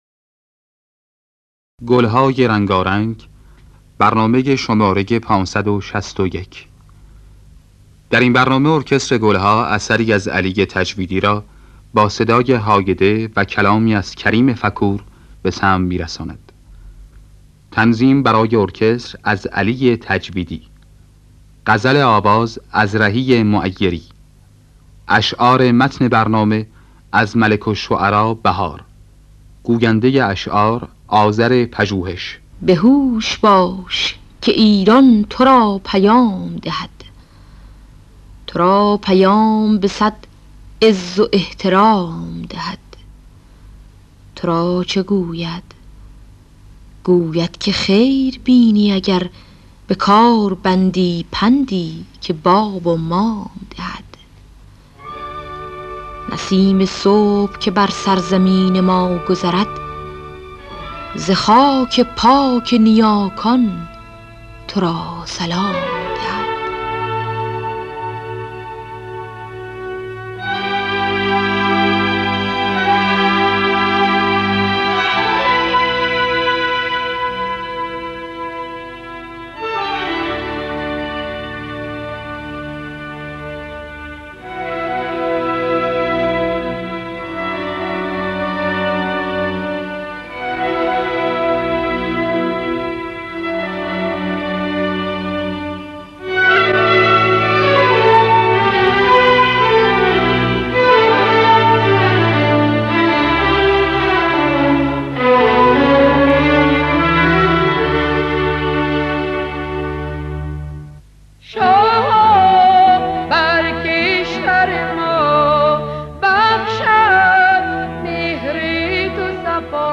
در دستگاه ماهور